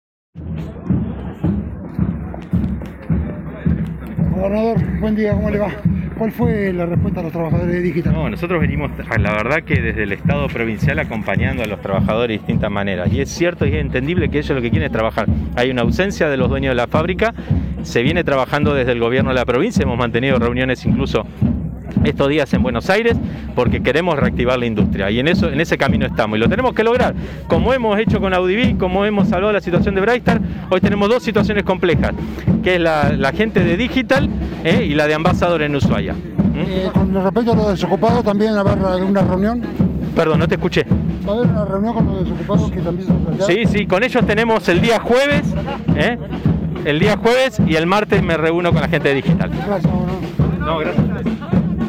En dialogo con este medio el gobernador señaló, “Desde el estado provincial acompañando a los trabajadores y es cierto y entendible que ellos lo que quieren es trabajar, hay una ausencia de los dueños de la fábrica, se viene trabajando desde el gobierno, hemos mantenido reuniones estos días en Buenos Aires porque queremos reactivar la industria y en ese camino estamos y lo tenemos que lograr, como hemos hecho con Audivic y como hemos salvado la situación de Brighstart.